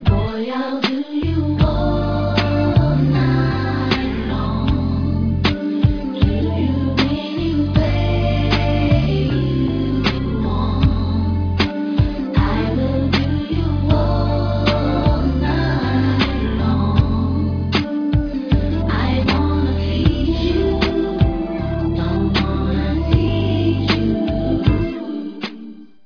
Written, produced and all synthesizers and keyboards